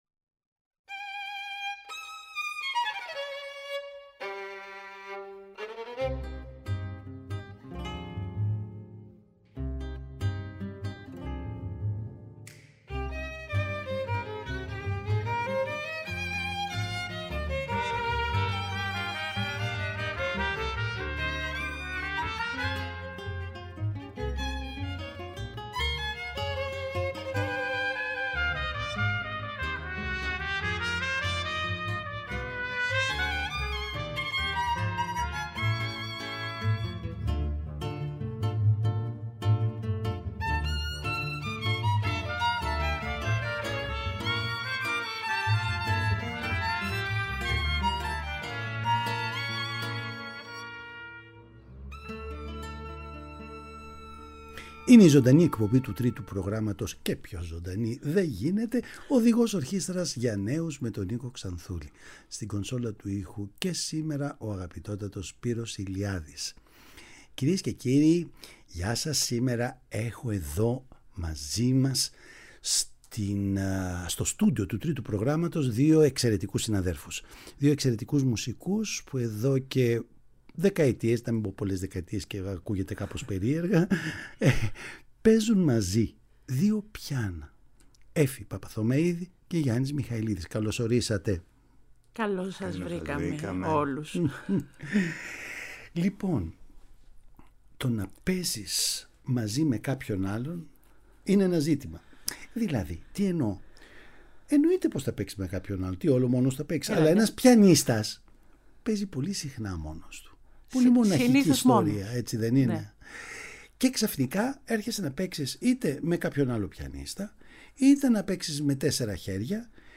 Τους καλέσαμε λοιπόν στο στούντιο του Τρίτου να μιλήσουμε για την Τέχνη τους.
Παραγωγή-Παρουσίαση: Νίκος Ξανθούλης